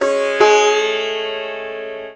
SITAR LINE18.wav